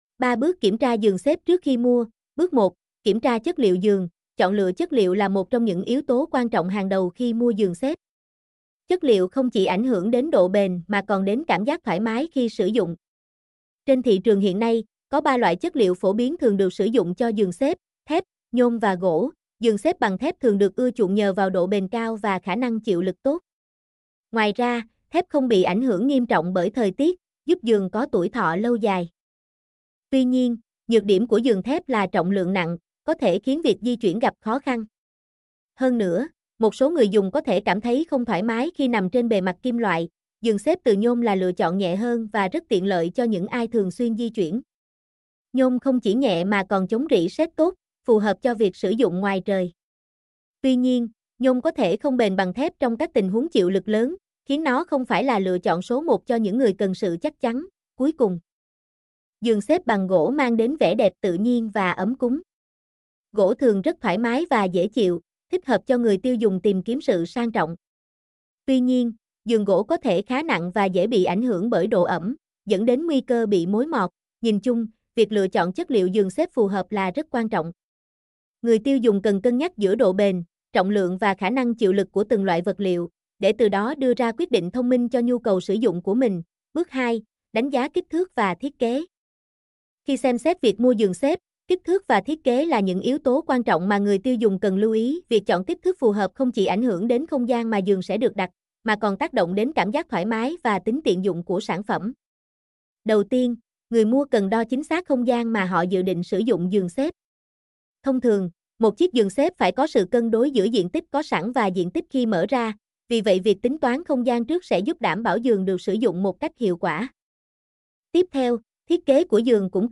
mp3-output-ttsfreedotcom-21.mp3